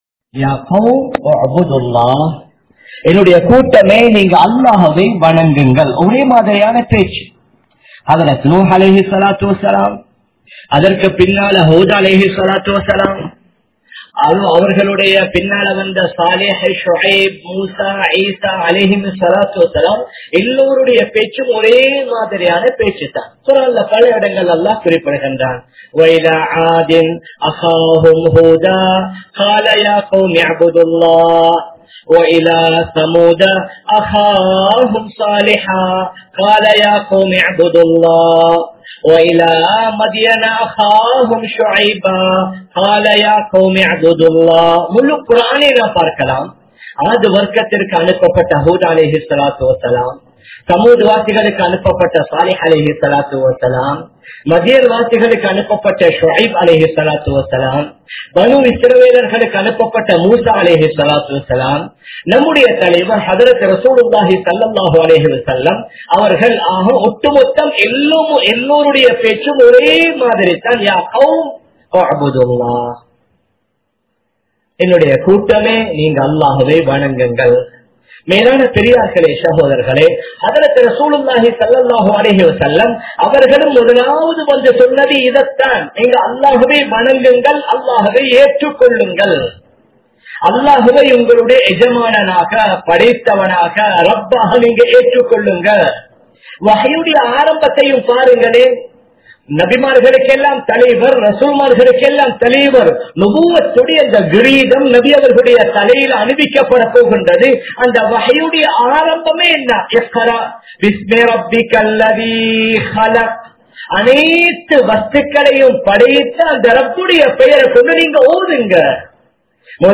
Dhauwaththin Avasiyam (தஃவத்தின் அவசியம்) | Audio Bayans | All Ceylon Muslim Youth Community | Addalaichenai
Oluvil, South Eastern University Jumua Masjith